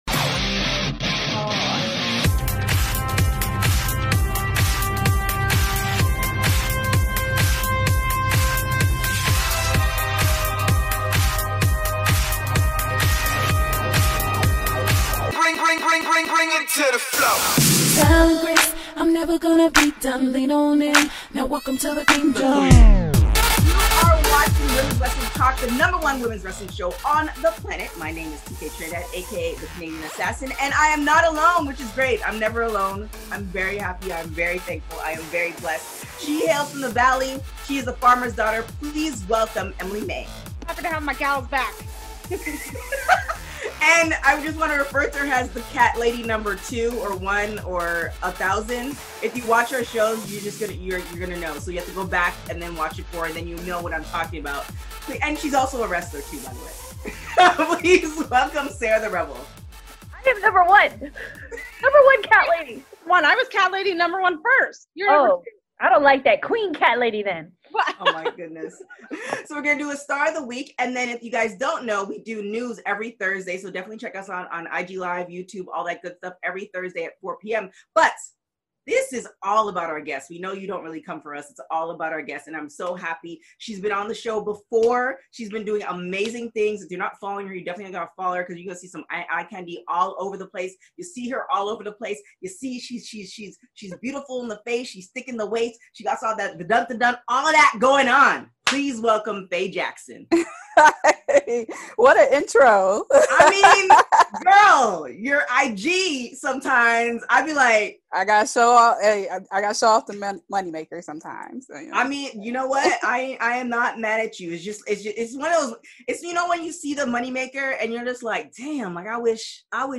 Women's Wrestling Talk / Interview